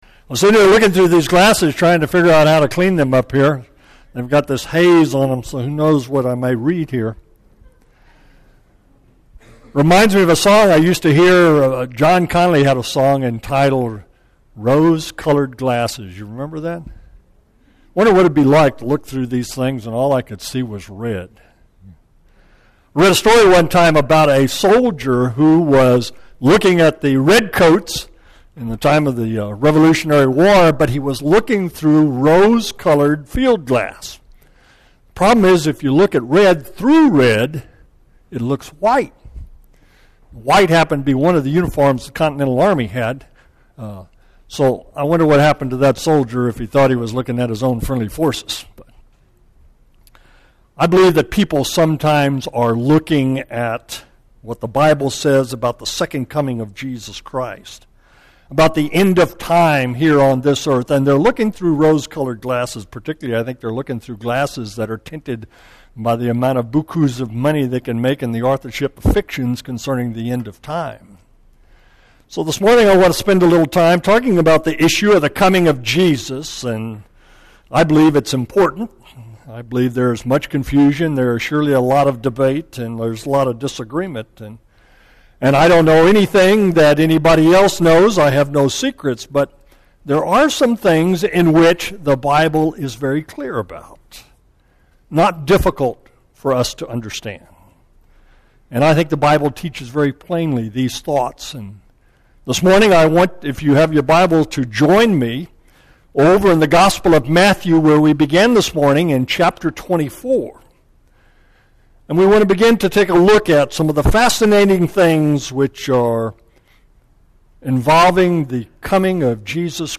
Audio Sermons - Babcock Road Christian Church